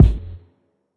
stchk_kick.wav